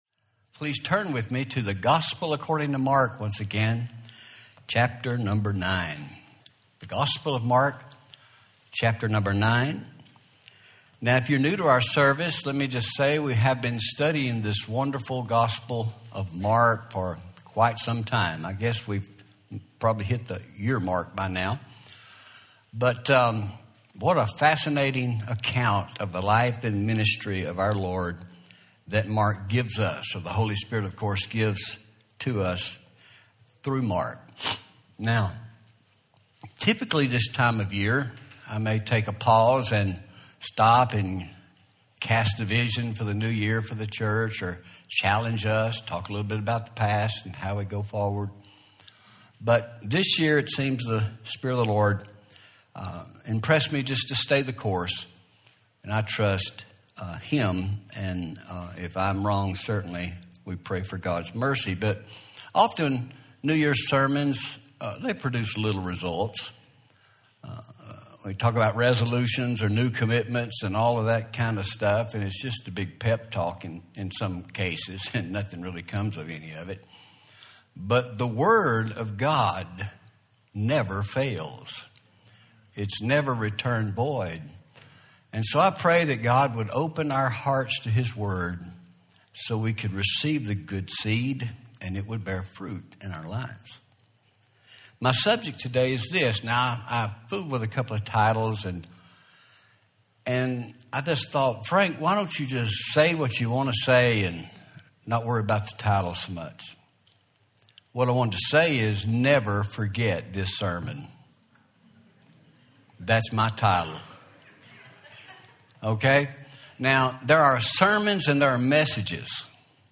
Home › Sermons › Never Forget This Sermon